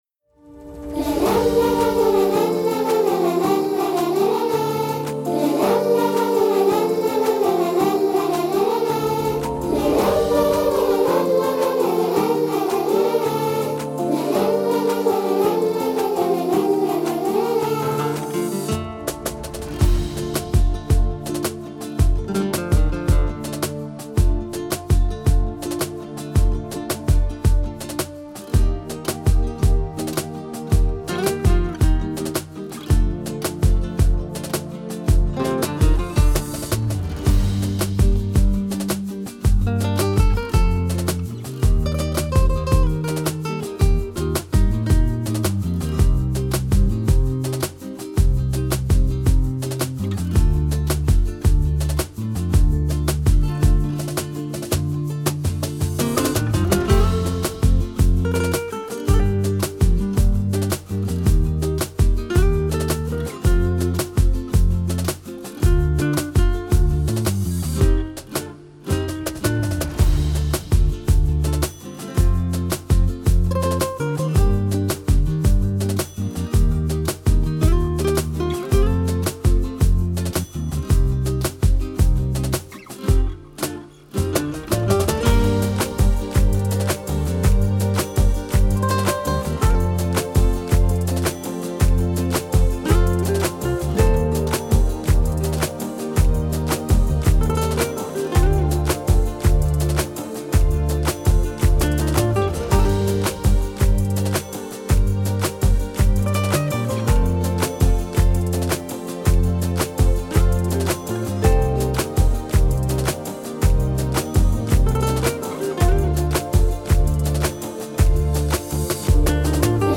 10 دسامبر 2025 2 نظر بیکلام ، سرود